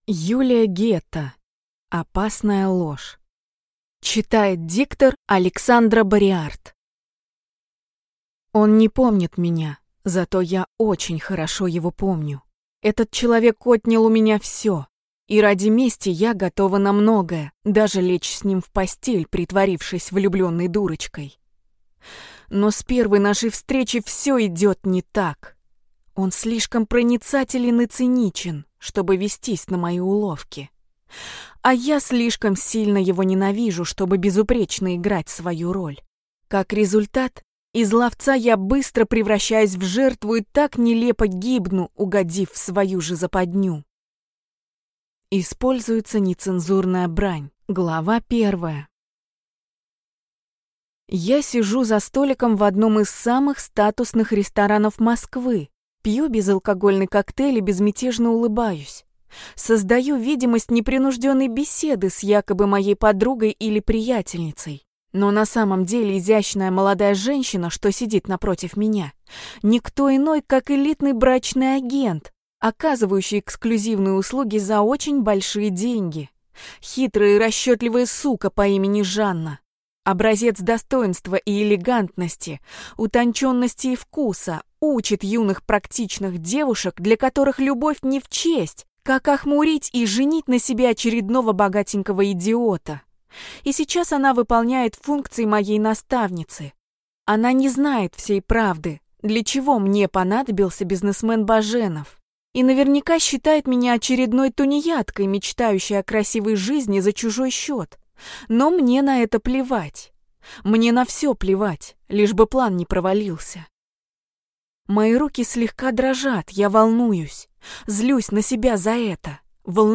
Аудиокнига Опасная ложь | Библиотека аудиокниг